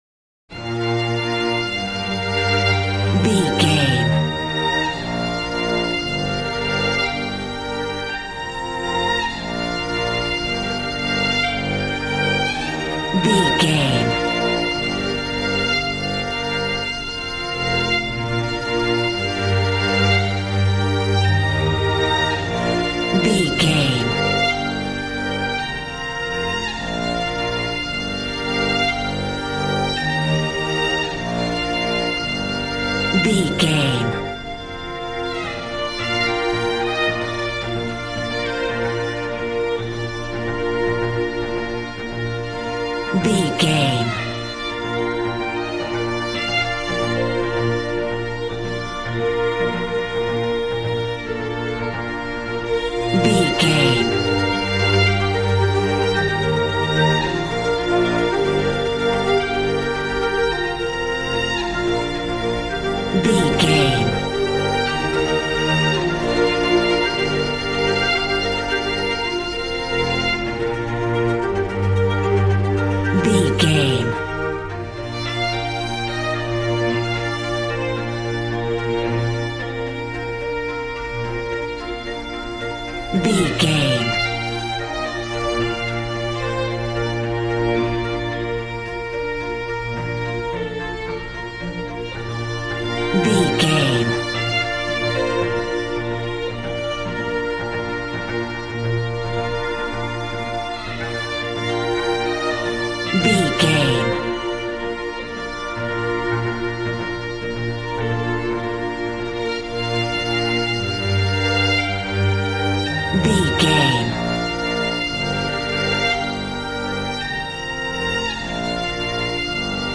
Modern film strings for romantic love themes.
Regal and romantic, a classy piece of classical music.
Ionian/Major
B♭
regal
cello
violin
brass